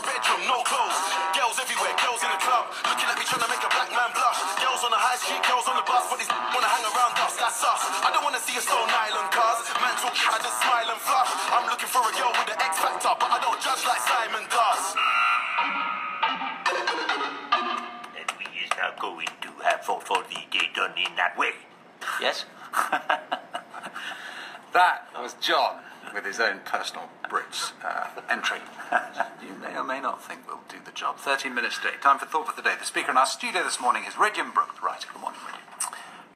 John Humphrys rapping